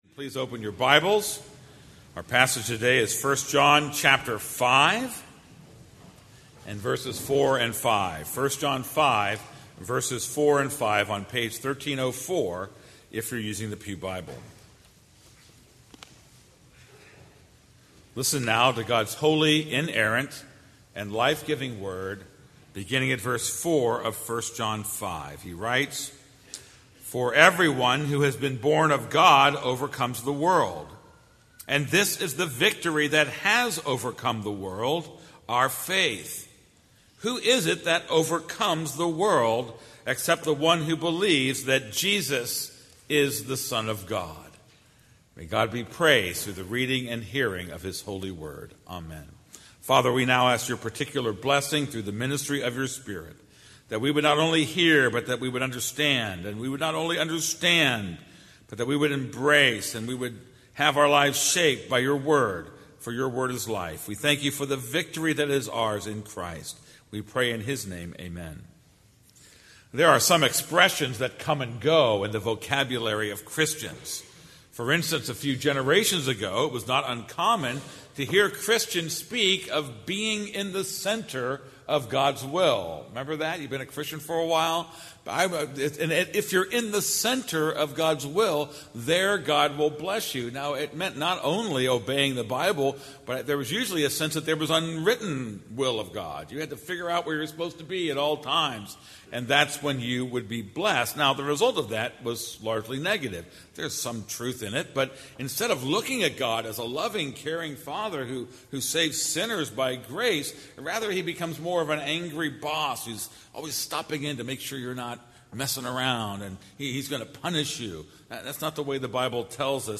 This is a sermon on 1 John 5:4-5.